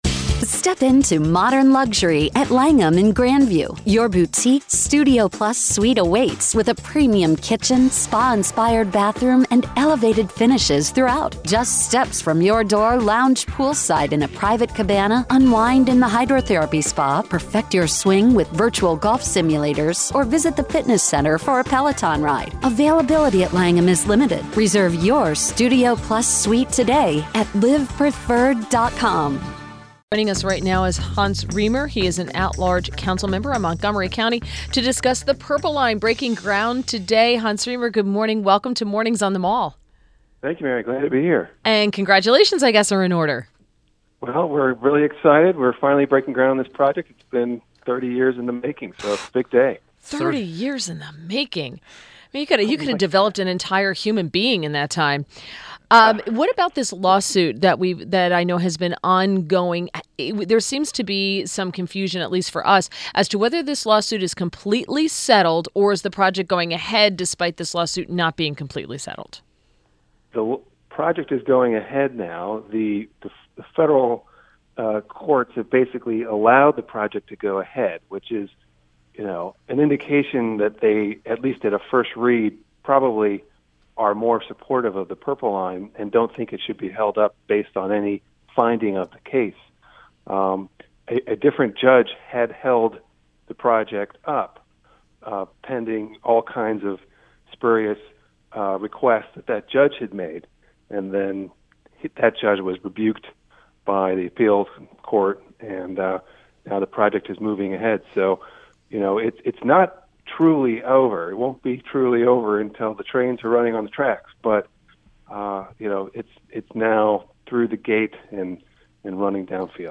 WMAL Interview - HANS RIEMER - 08.28.17
HANS RIEMER - At-large council member in Montgomery County, MD